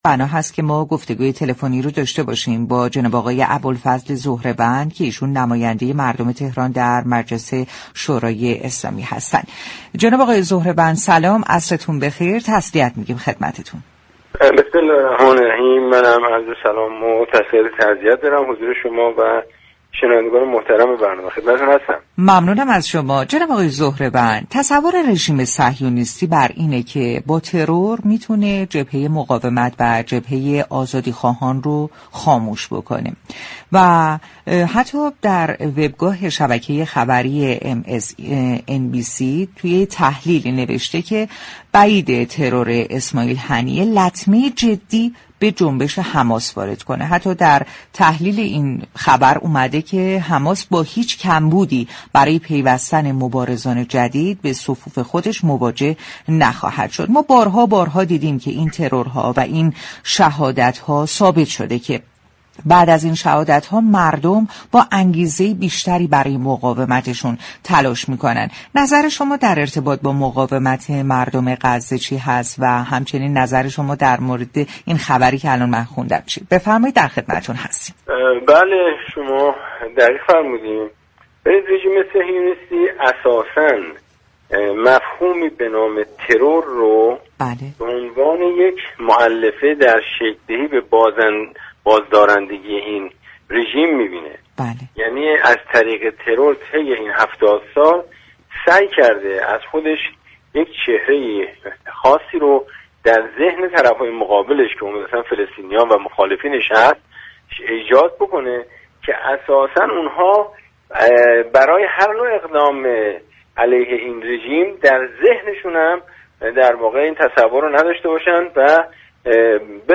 به گزارش پایگاه اطلاع رسانی رادیو تهران؛ ابوالفضل ظهره وند نماینده مجلس دوازدهم در گفتگو با ویژه برنامه«مجاهد شدید» كه به مناسبت شهادت اسماعیل هنیه رئیس دفتر سیاسی حماس از رادیو تهران پخش شد گفت: رژیم صهیونیستی از ترور به عنوان عامل بازدارنده در برابر فلسطینیان و مخالفانش استفاده می‌كند تا آنها بدانند مورد حذف فیزیكی قرار خواهند گرفت.